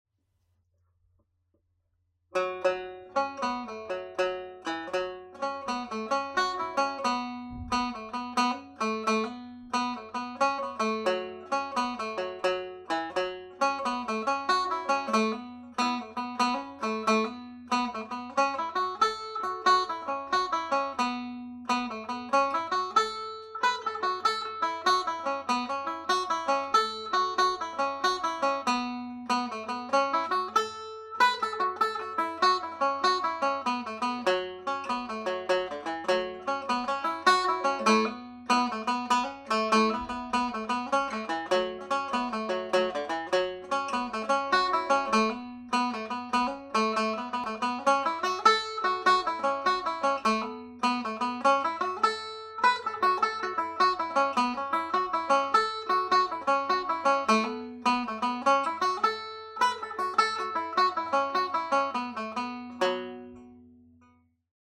Slip Jig (F sharp Minor)
played normal speed